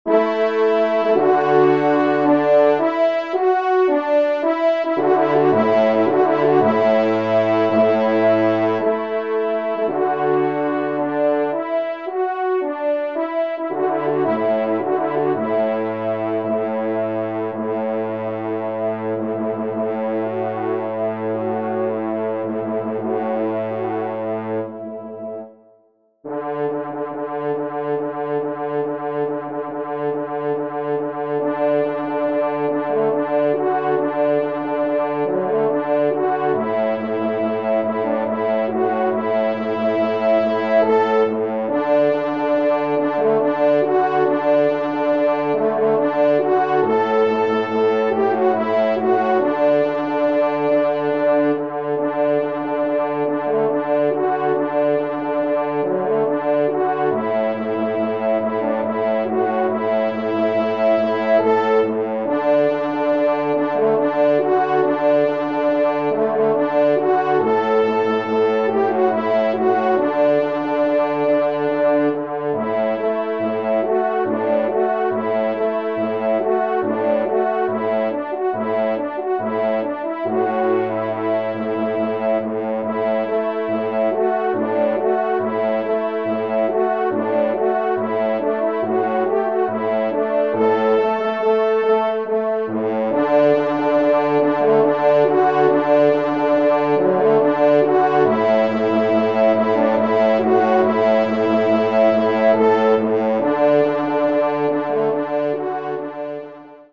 4e Trompe